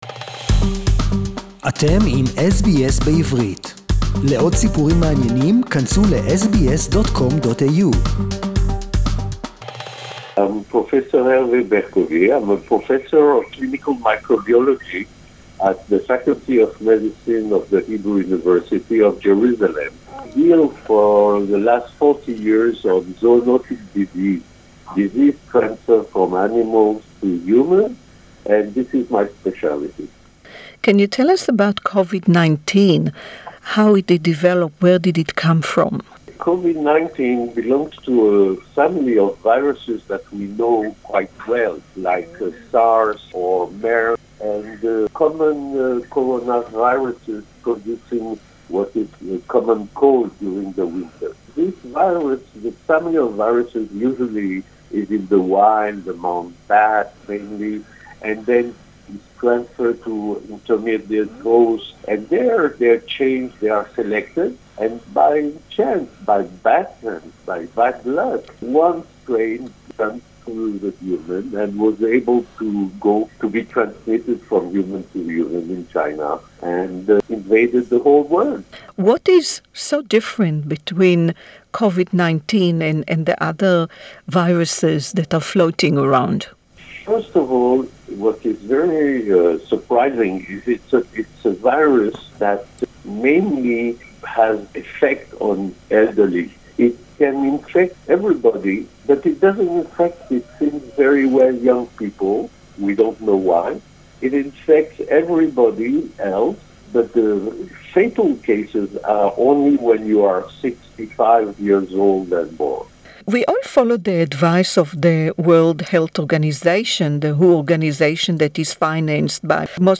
WHO failed to protect the elderly " (English interview)